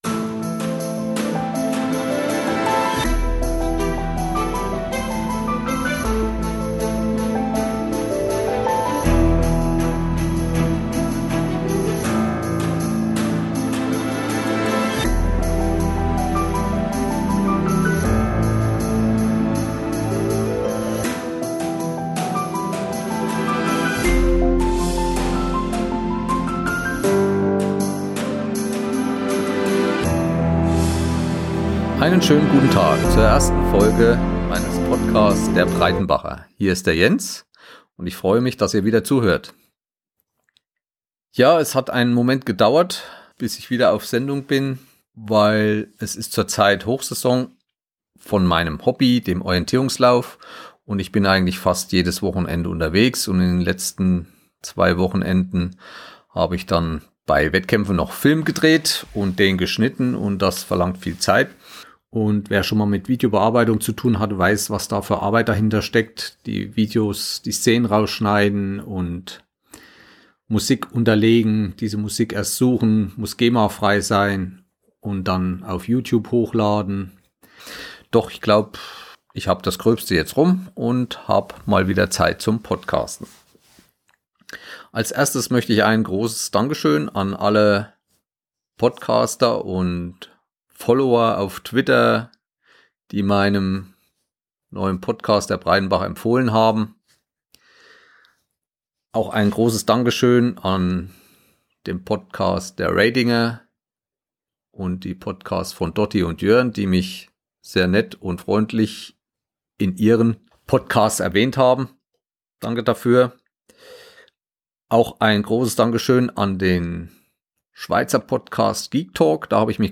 Produziert mit einem ZOOM H1 und Wavelab LE